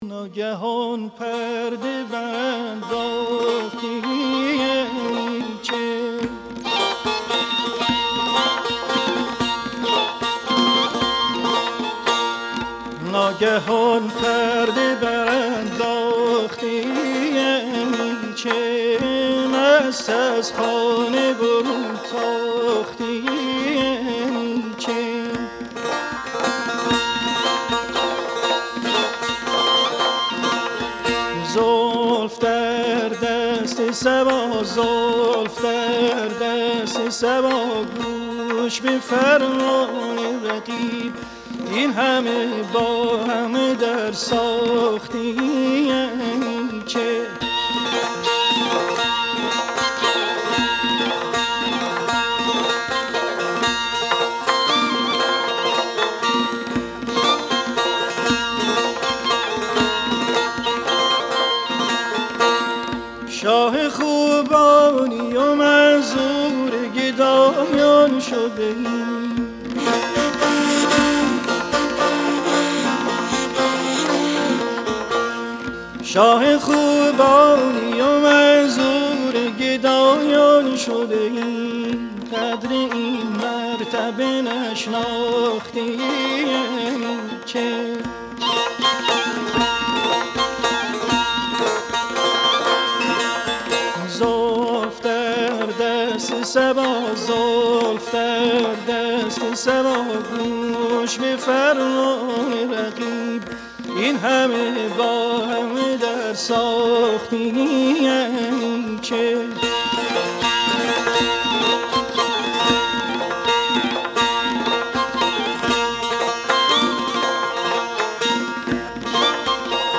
آهنگ سنتی